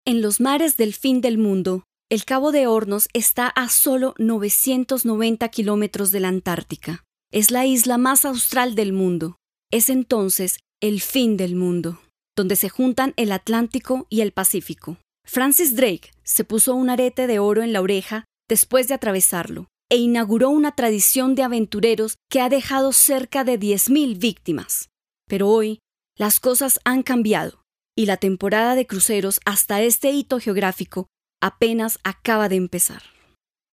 kastilisch
Sprechprobe: eLearning (Muttersprache):